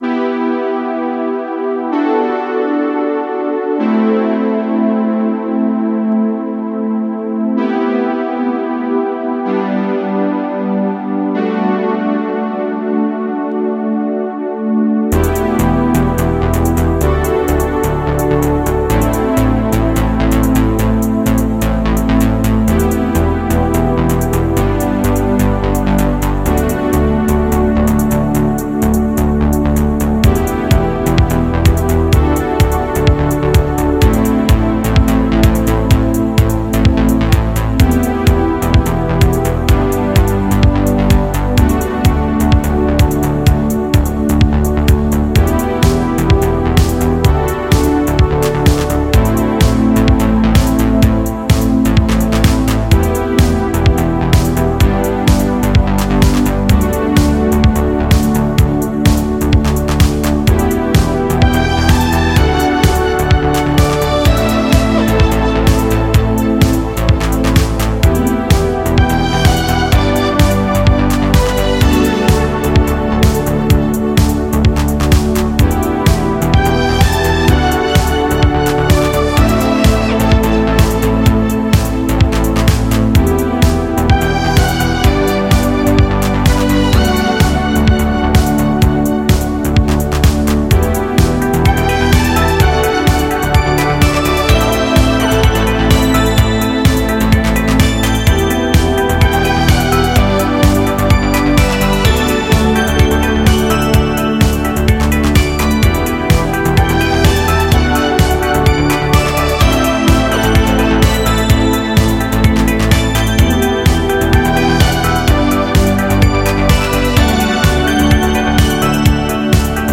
Жанр: Synthwave, Retrowave, Spacewave, Dreamwave, Electronic